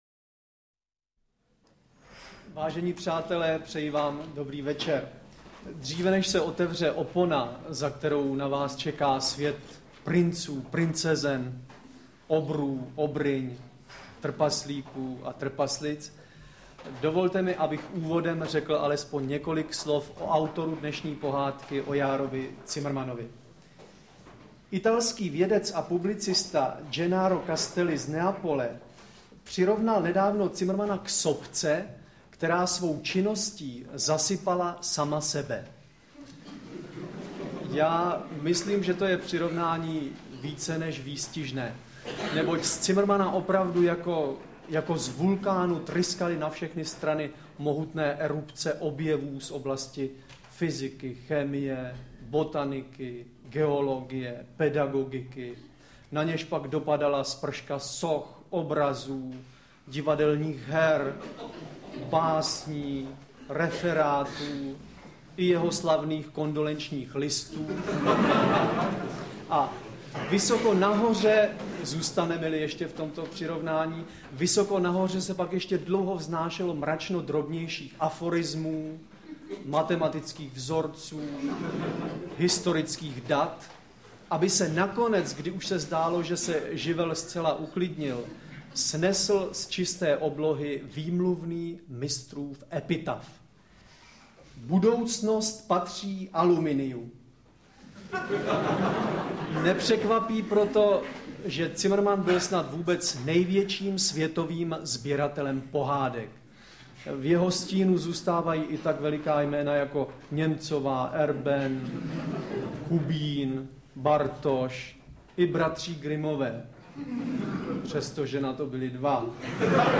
Interpreti:  Ladislav Smoljak, Zdeněk Svěrák
Stejně jako většina ostatních představení Divadla Járy Cimrmana, sestává Dlouhý, Široký a Krátkozraký ze dvou částí - odborné části (formou přednášky nebo kratších referátů) o životě a díle Járy Cimrmana a v druhé části pak ucelenějšího zpracování některého jeho díla - v tomto případě pohádky Dlouhý, Široký a Krátkozraký.
AudioKniha ke stažení, 2 x mp3, délka 54 min., velikost 48,9 MB, česky